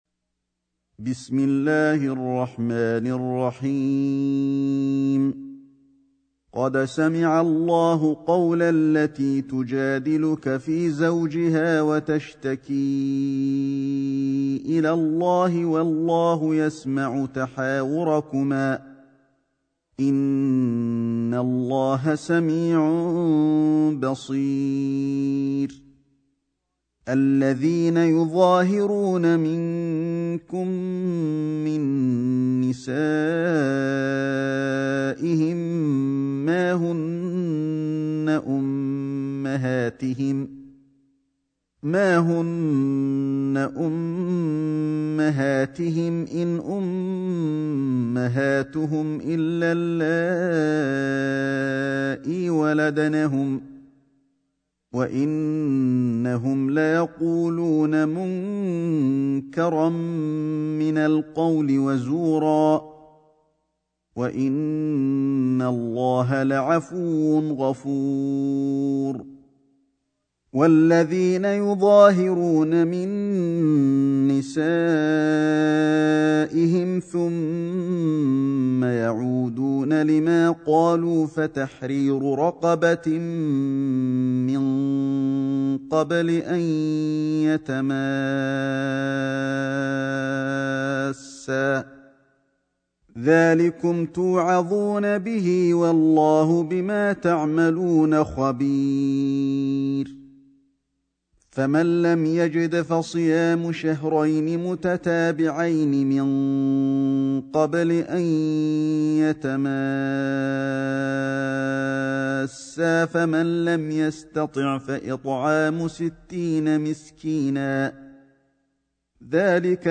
سورة المجادلة > مصحف الشيخ علي الحذيفي ( رواية شعبة عن عاصم ) > المصحف - تلاوات الحرمين